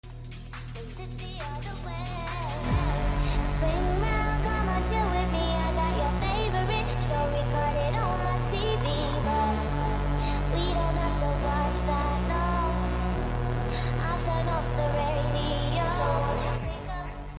hot/ badass